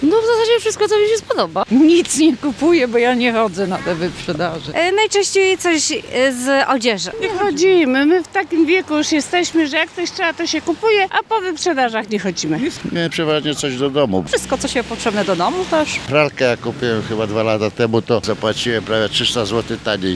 Nasz reporter zapytał mieszkańców Stargardu, co kupują najczęściej.